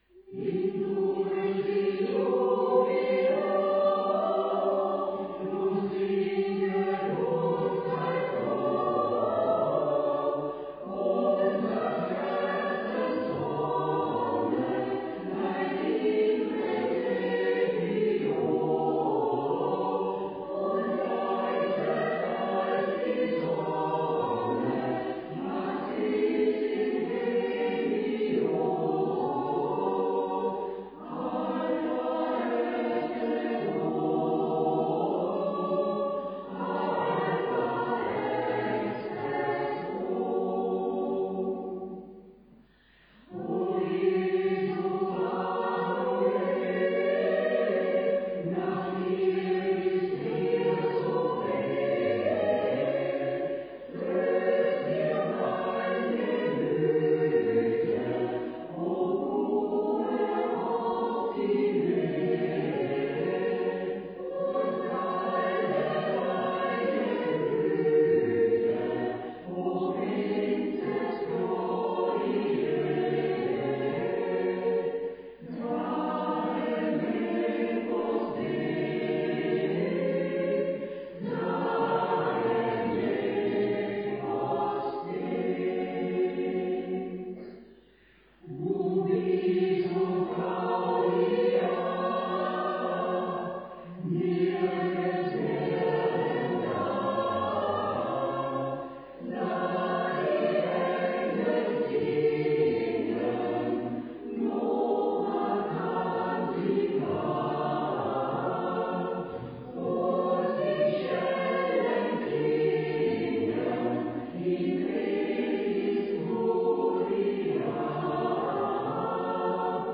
Adventkonzert 2019
Am 1. Dezember hat der Advent gleich stimmungsvoll mit dem heurigen Adventkonzert begonnen.